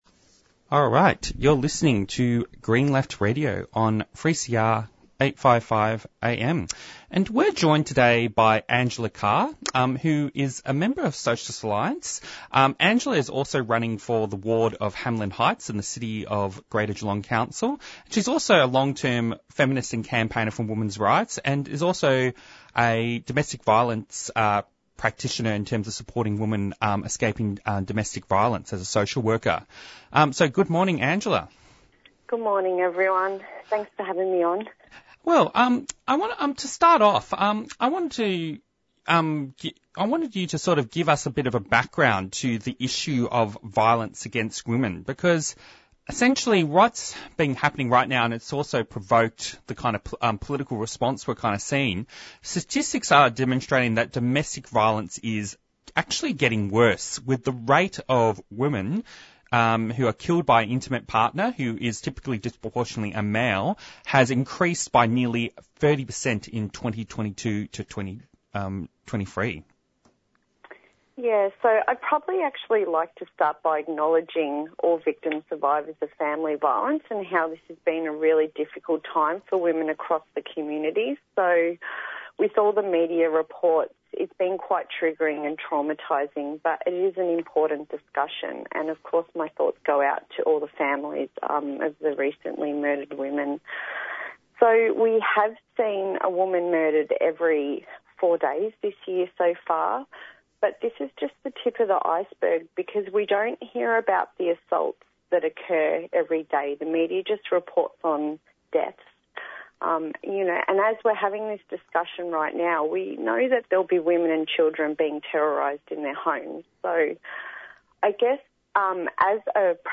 Presenters discuss the inspiring pro-palestine campus movement in the United States and how it has been met with severe police repression. Report on a victory of the Hume for Palestine campaign against Heat Treatment Australia following sustained community pickets. Interviews and Discussions